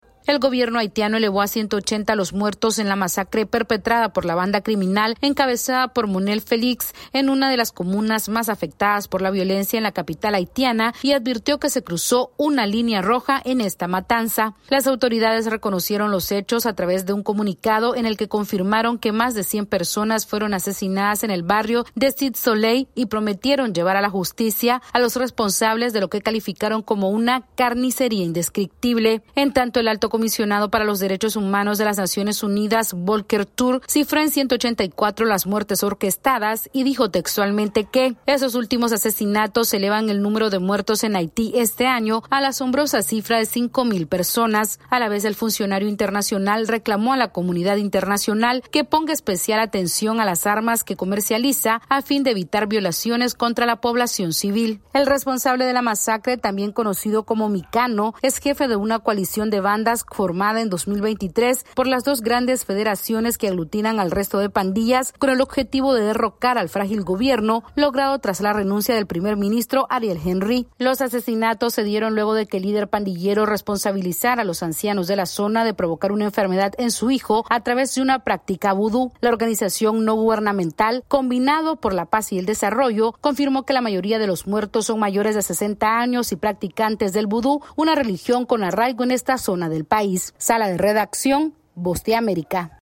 El líder de una pandilla en Haití es acusado de ordenar la masacre de más de 180 adultos mayores para vengar la muerte de su hijo, mientras el gobierno alerta sobre el cruce de una peligrosa “línea roja”. Esta es una actualización de nuestra Sala de Redacción....